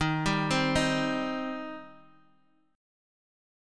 D#6 Chord